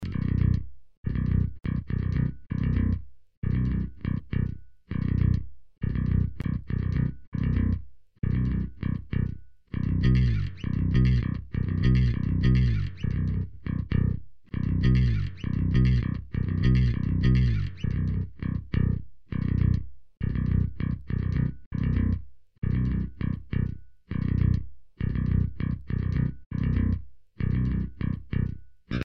���� ����� - TSE X30/Ozone 4 - Alternative Metal